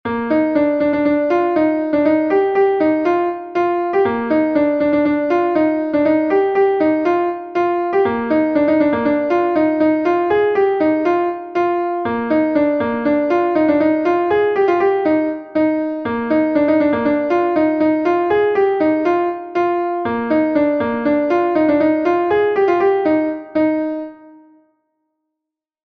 Gavotenn Ploure is a Gavotte from Brittany